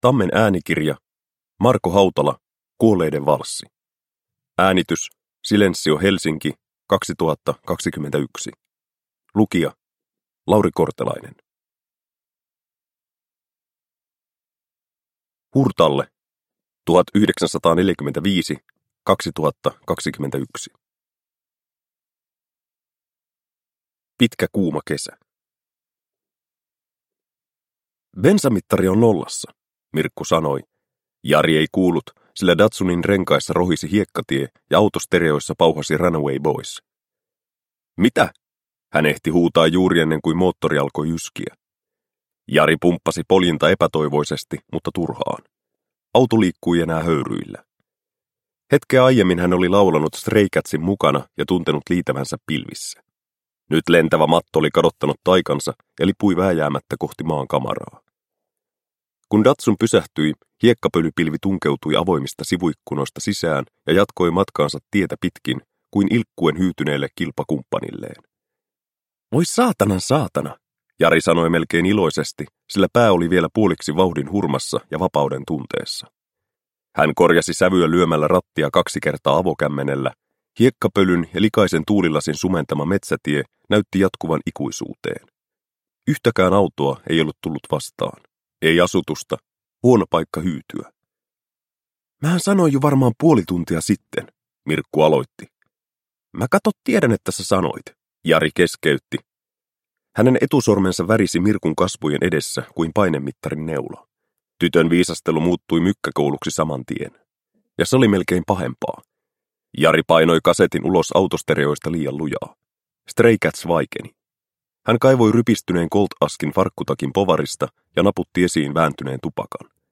Kuolleiden valssi – Ljudbok – Laddas ner